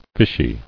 [fish·y]